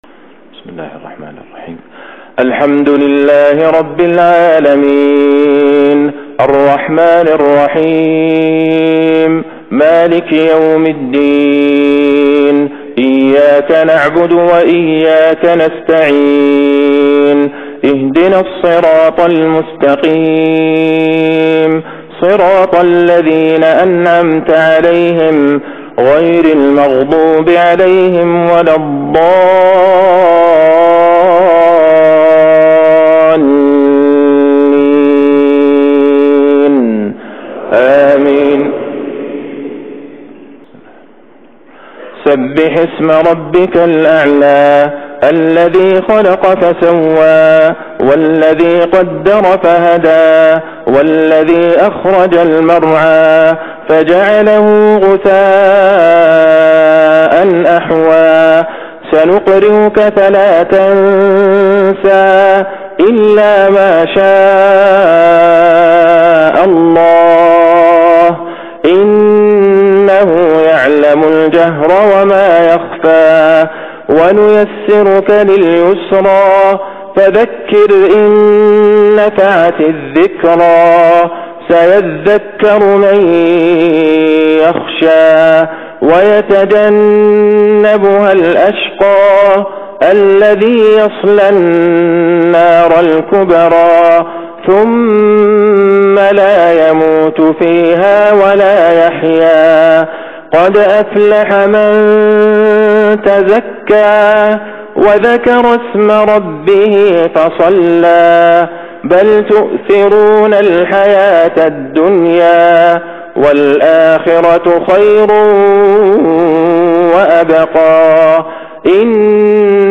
صلاة الجمعة للشيخ عبدالله البعيجان بدولة روسيا 25 ربيع الآخر 1447هـ > زيارة الشيخ عبدالله البعيجان لـ موسكو > تلاوات و جهود الشيخ عبدالله البعيجان > المزيد - تلاوات الحرمين